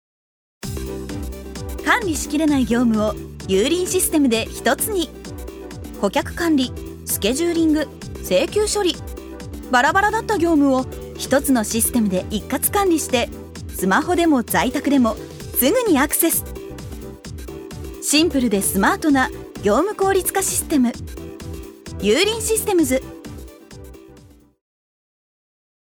ジュニア：女性
ナレーション３